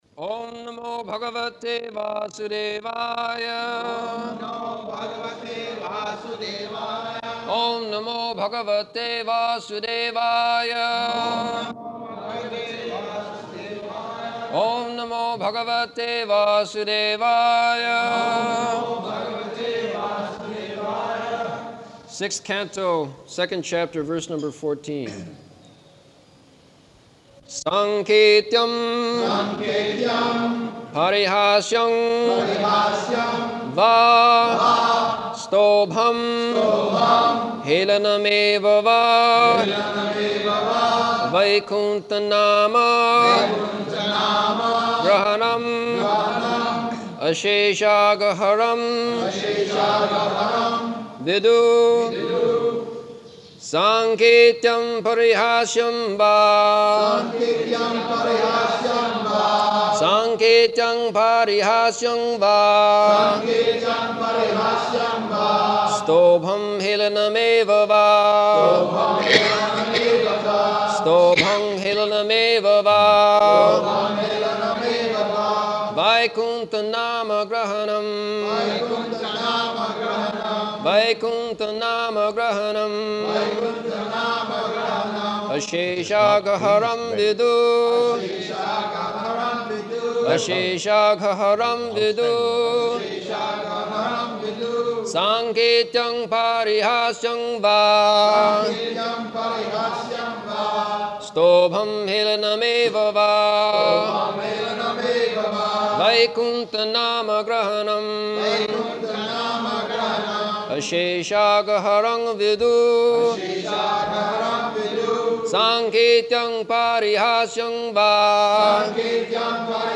September 17th 1975 Location: Vṛndāvana Audio file
[devotees repeat] Sixth Canto, Second Chapter, verse number fourteen.